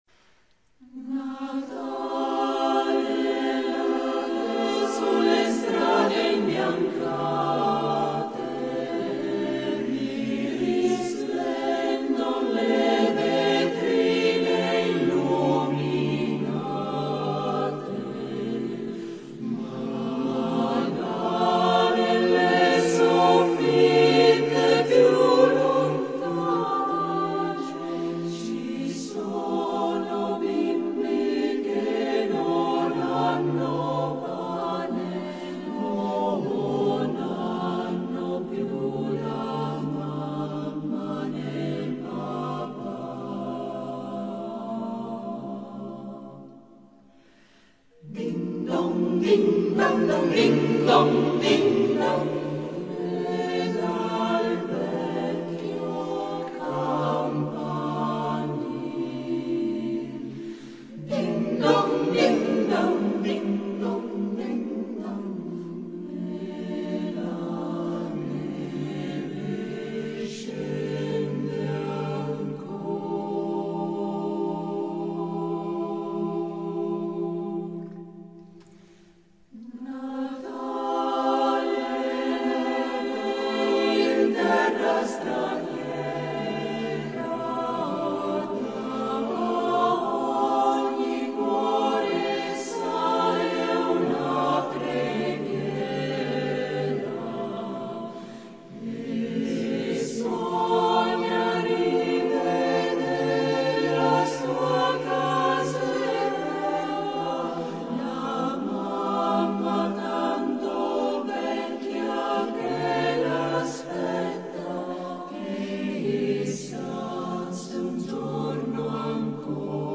Number of voices: 4vv Voicing: SATB Genre: Secular, Christmas (secular)
Language: Italian Instruments: A cappella